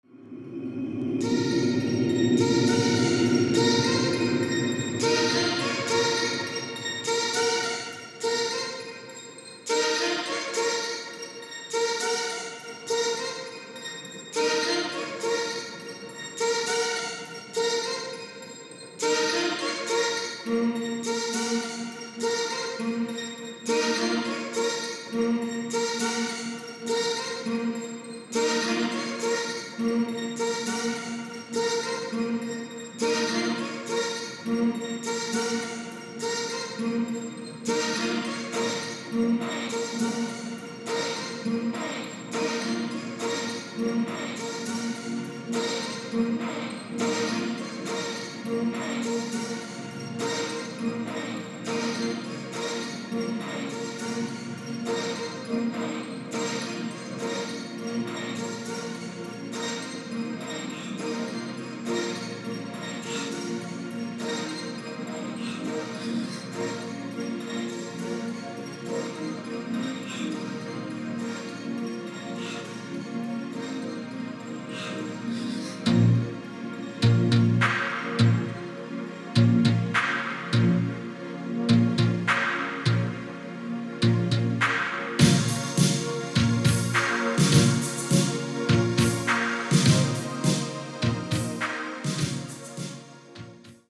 この辺りはCosmicで注目されましたね！！！